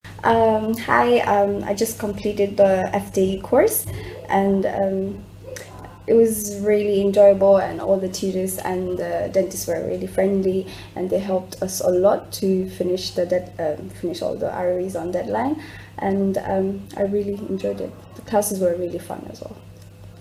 Below, you’ll find a collection of testimonials from past participants.
Audio Testimonials